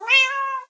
meow3.ogg